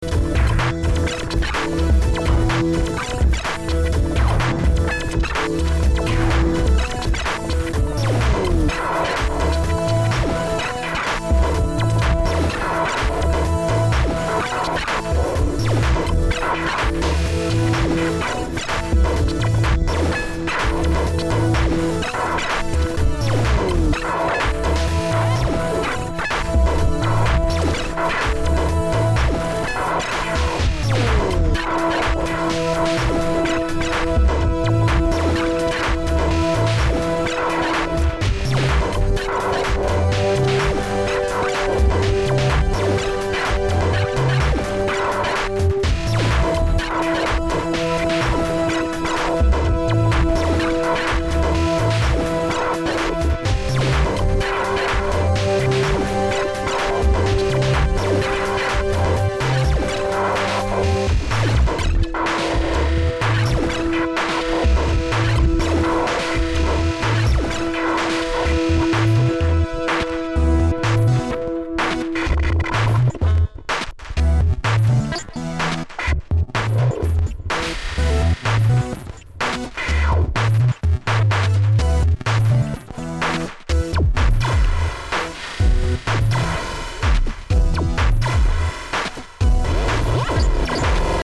Electro